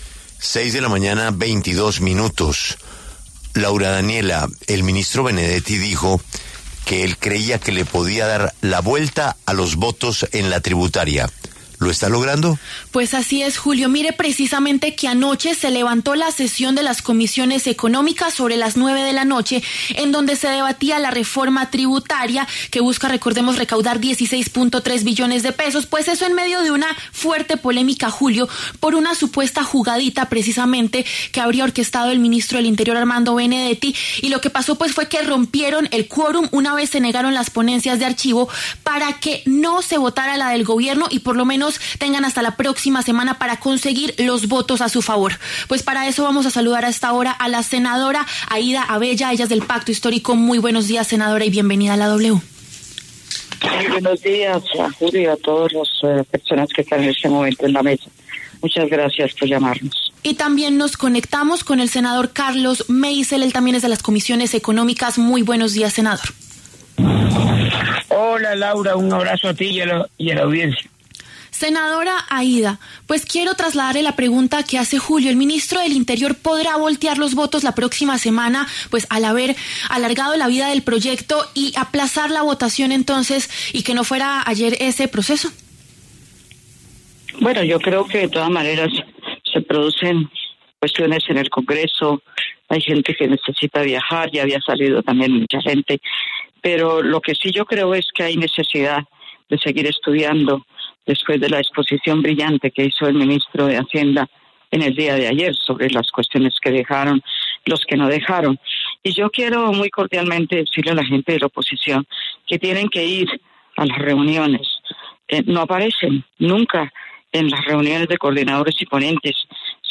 En los micrófonos de La W, los senadores Aida Avella y Carlos Meisel debatieron al respecto.